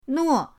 nuo4.mp3